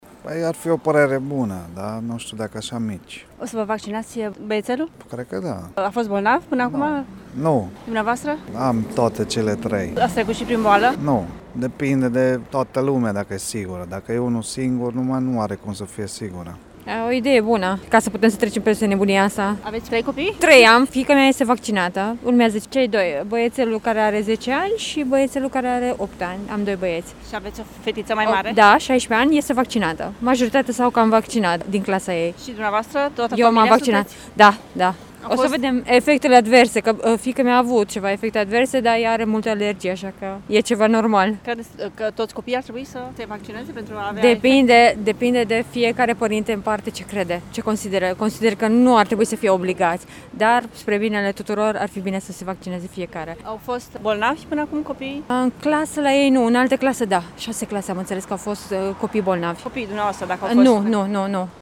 La Târgu Mureș, vaccinarea copiilor este așteptată în special de către părinții, care la rândul lor sunt imunizați: